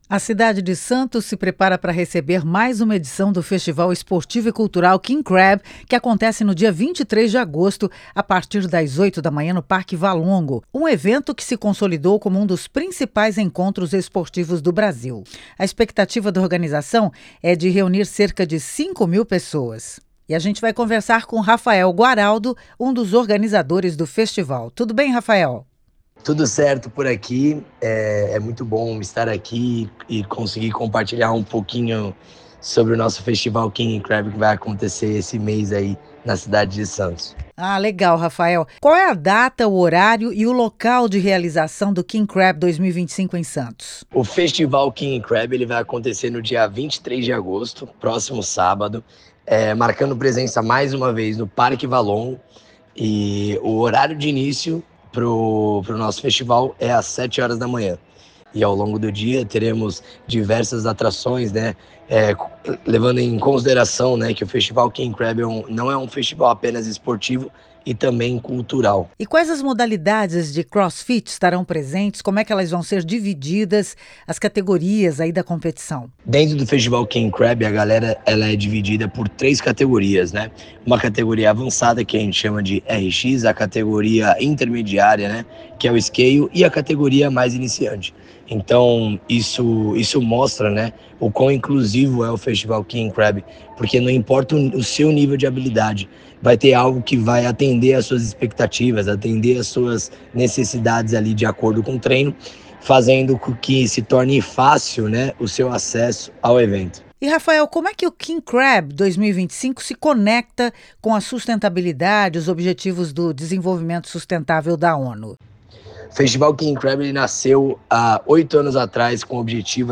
entrevista.wav